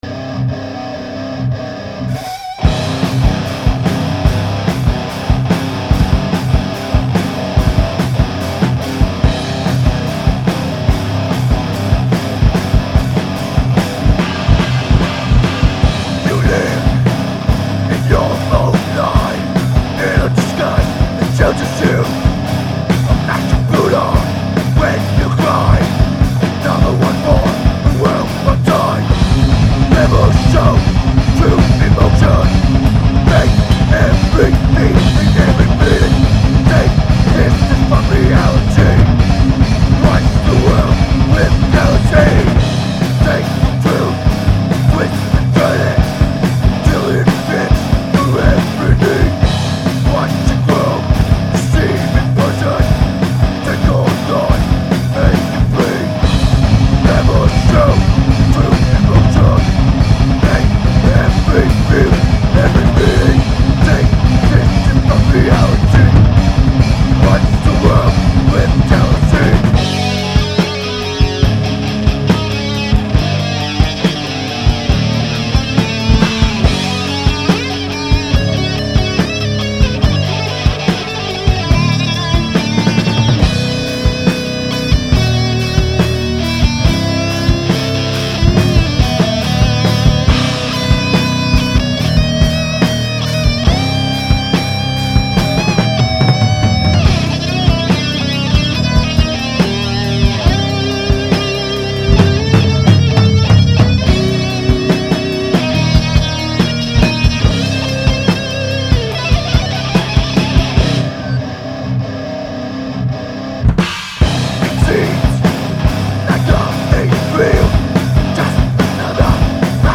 drums